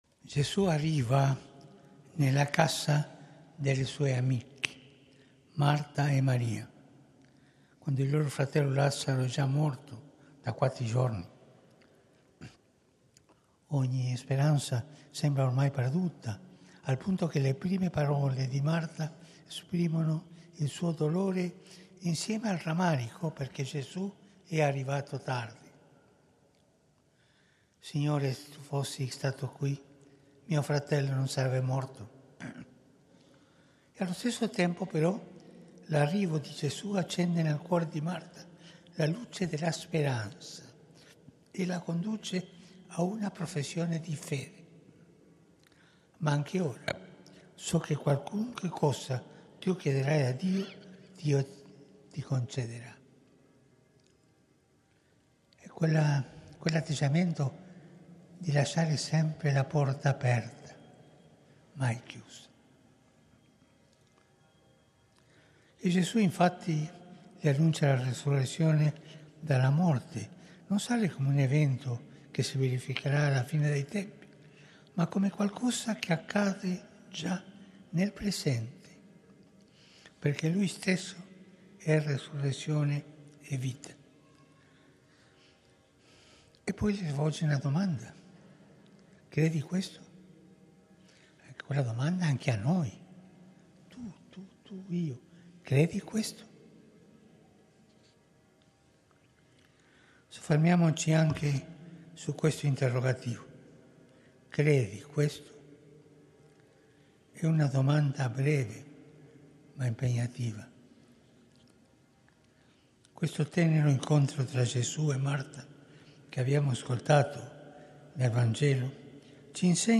CELEBRAZIONE DEI SECONDI VESPRILVIII SETTIMANA DI PREGHIERA PER L’UNITÀ DEI CRISTIANI
OMELIA DEL SANTO PADRE FRANCESCO